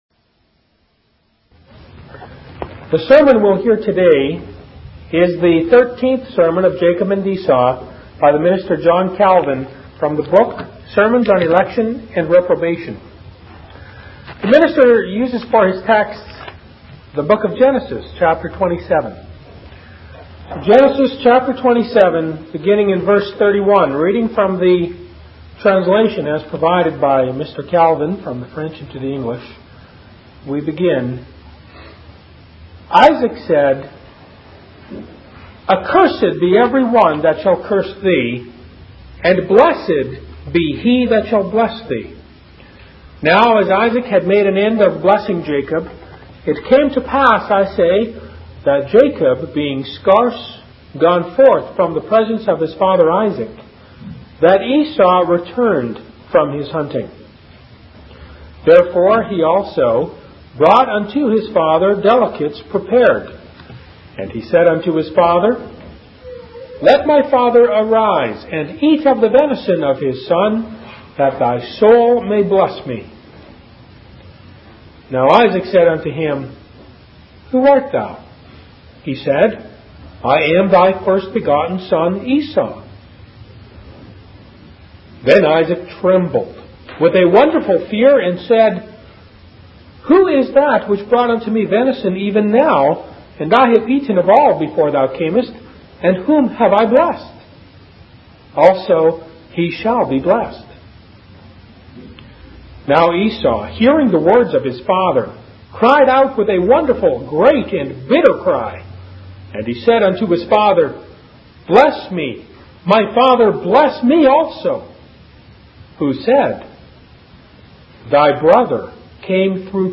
In this sermon, John Calvin focuses on the story of Isaac and his submission to the will of God. He emphasizes the importance of maintaining a good reputation and not being ashamed to change one's ways when necessary. Calvin warns against hardening oneself in obstinacy and urges listeners to abstain from doing any harm to those whom God has chosen.